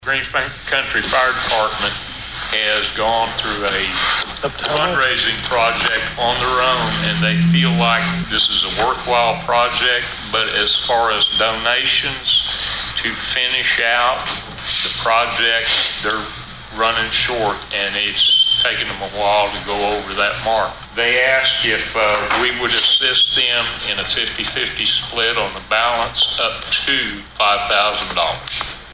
Here is District Three Commissioner Charlie Cartwright with more on the cause.